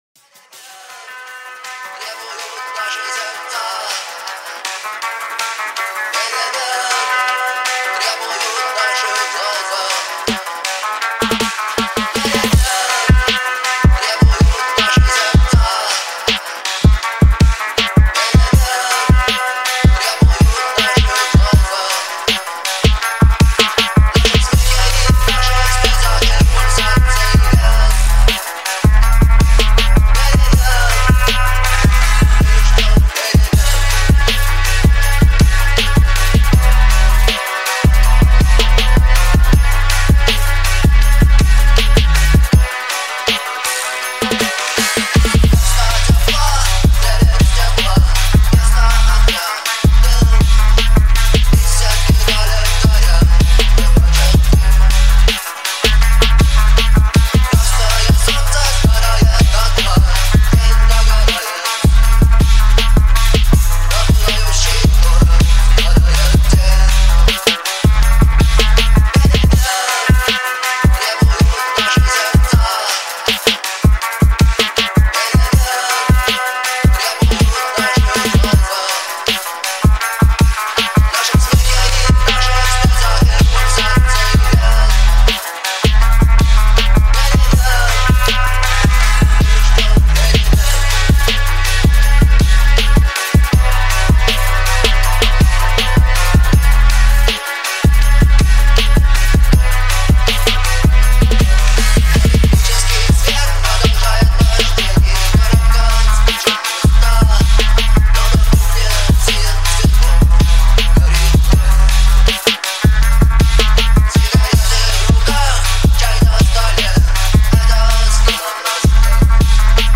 Phonk Remix